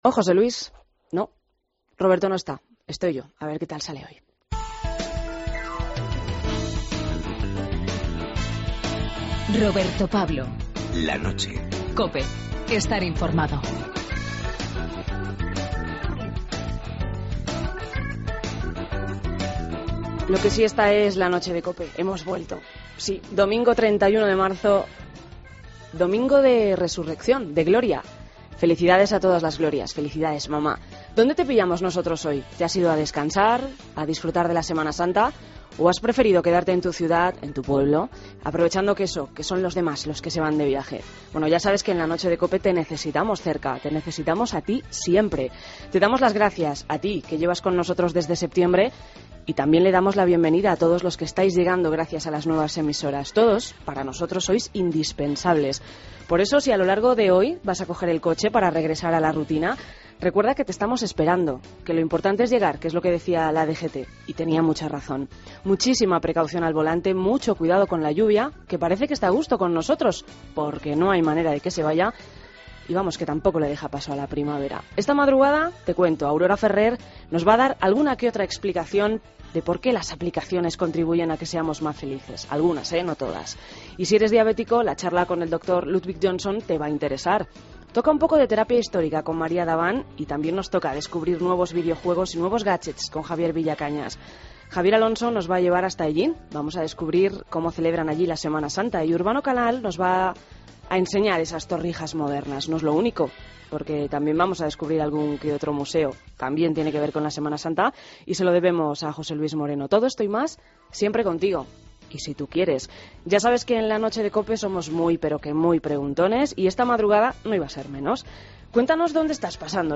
AUDIO: Queremos saber cuál ha sido tu Semana Santa más especial y preguntamos en la calle a los ciudadanos para que nos las cuenten.